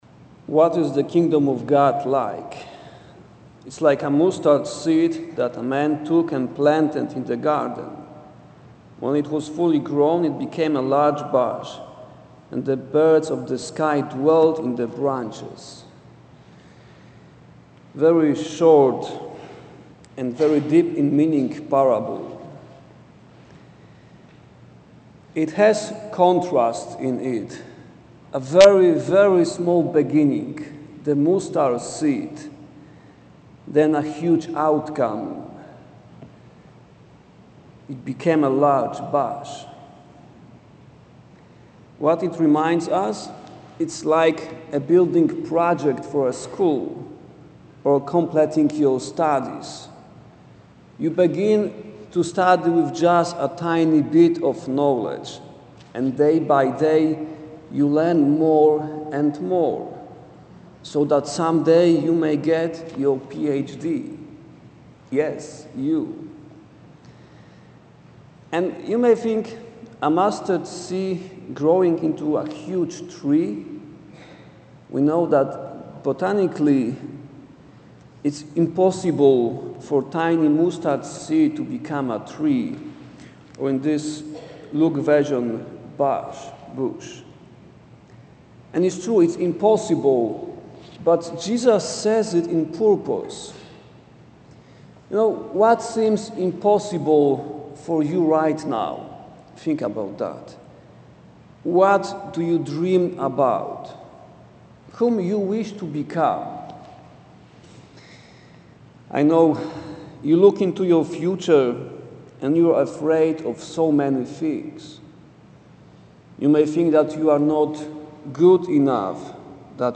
school-mass-mustard-1.mp3